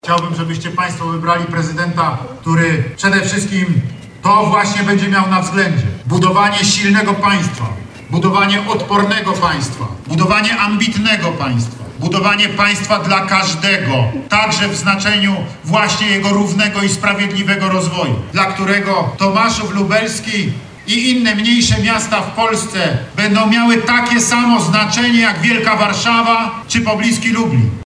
Prezydent RP Andrzej Duda spotkał się z mieszkańcami Tomaszowa Lubelskiego. Podczas przemówienia mówił między innymi o strategicznych inwestycjach takich jak Centralny Port Komunikacyjny czy Via Carpatia. Przyznał też, że w nadchodzących wyborach zagłosuje na Karola Nawrockiego.